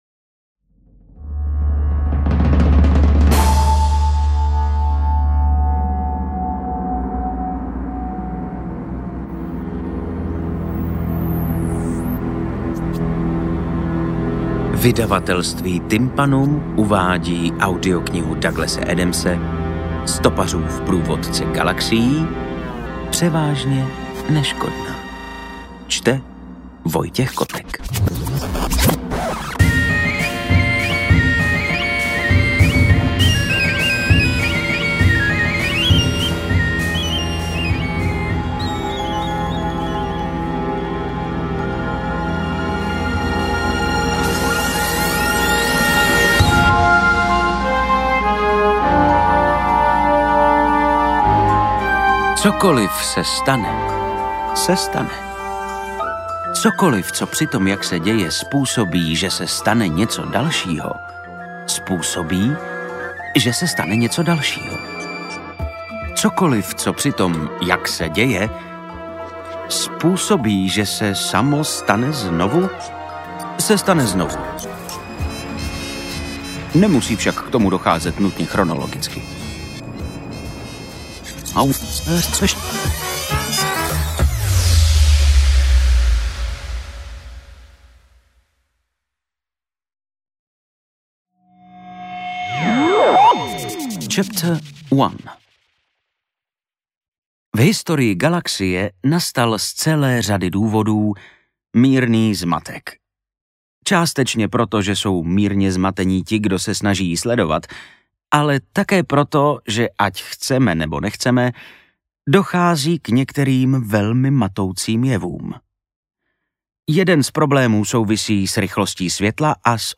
Interpret:  Vojta Kotek
AudioKniha ke stažení, 42 x mp3, délka 7 hod. 51 min., velikost 429,3 MB, česky